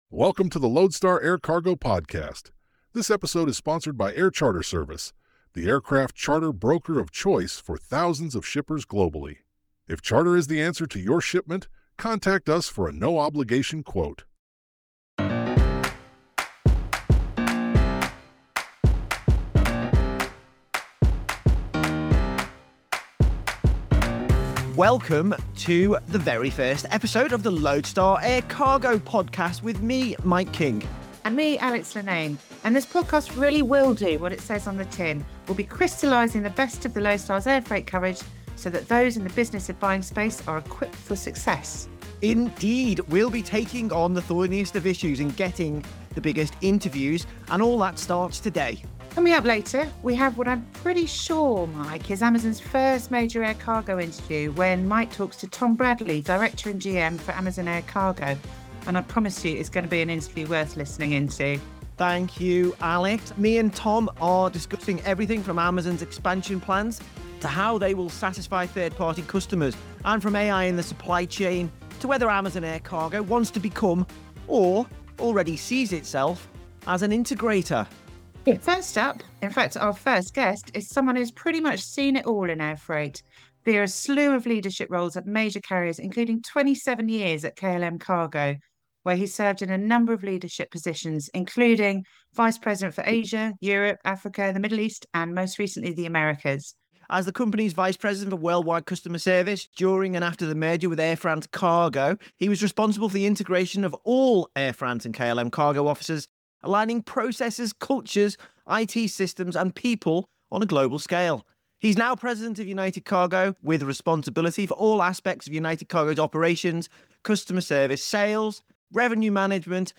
this episode features two major interviews that shed light on the evolving air cargo landscape.